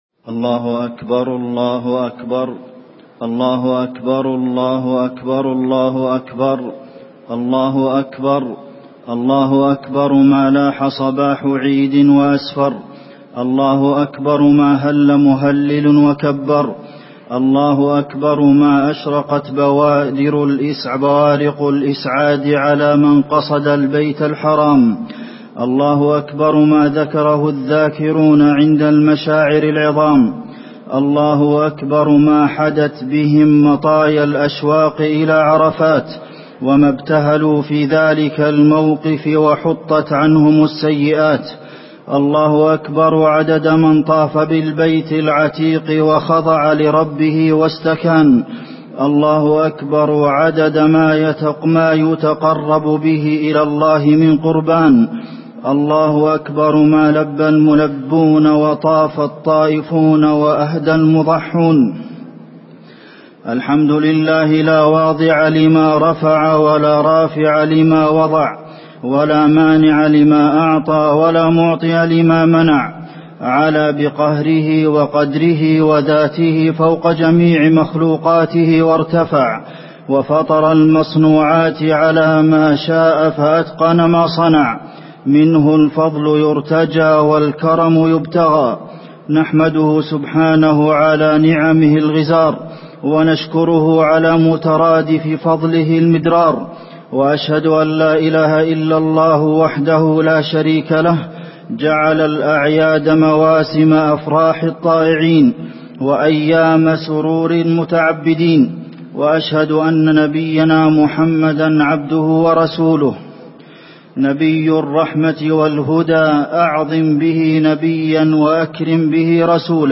خطبة عيد الأضحى - المدينة - الشيخ عبدالمحسن القاسم - الموقع الرسمي لرئاسة الشؤون الدينية بالمسجد النبوي والمسجد الحرام
المكان: المسجد النبوي